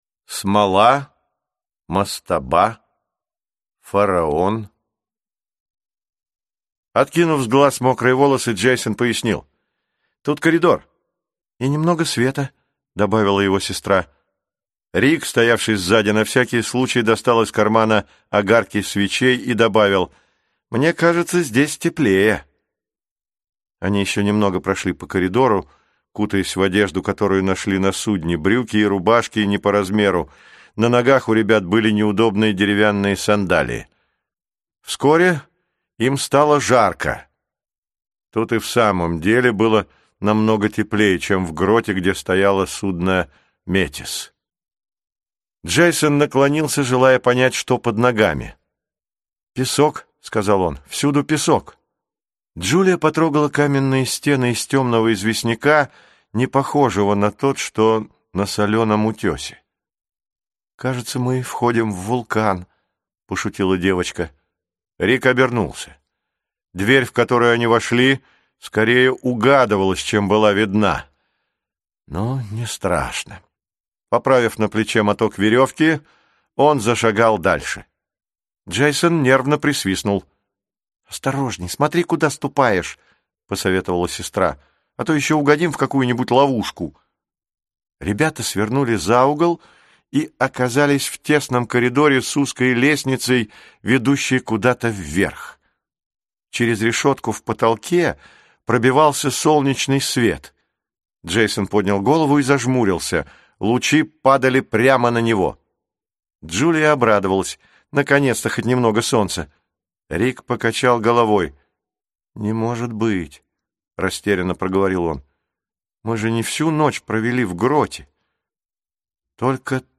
Аудиокнига Лавка забытых карт